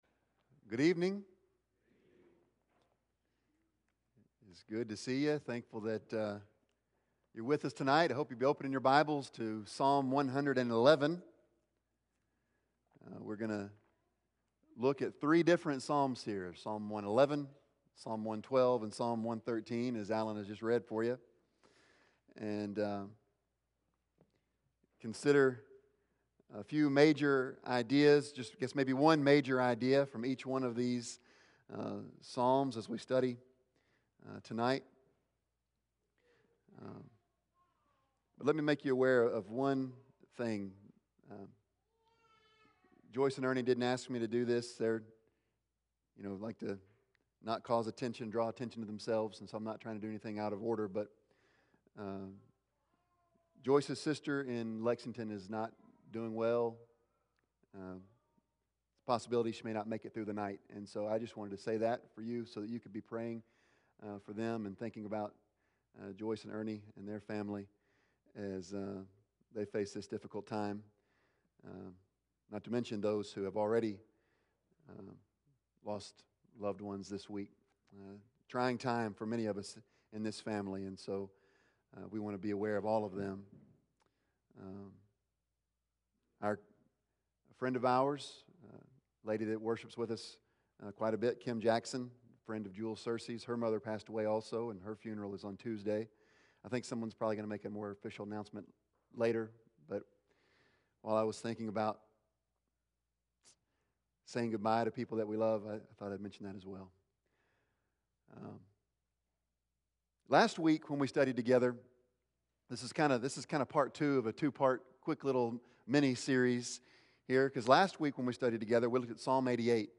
Psalms 111-113 Service Type: Sunday Evening « Motivation for Obedience Be Thankful for the Works of the LORD!